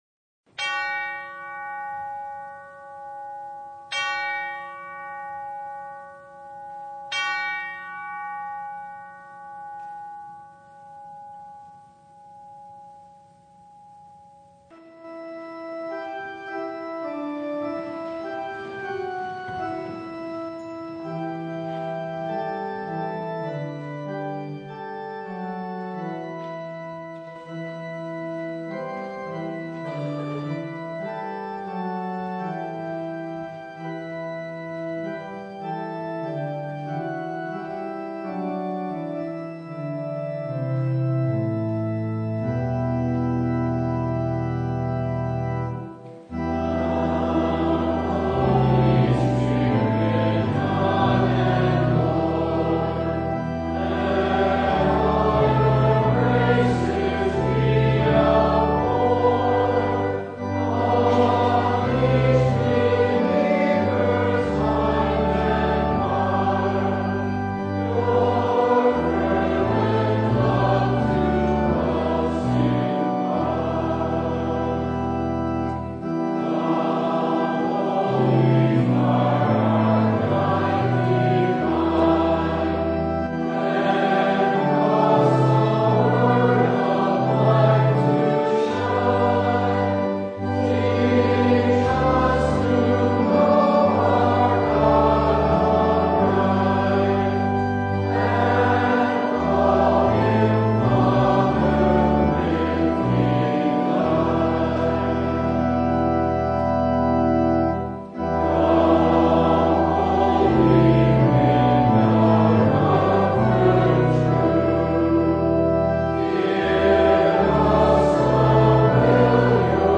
Service Type: Sunday
Download Files Notes Bulletin Topics: Full Service « Don’t You Care If We Perish?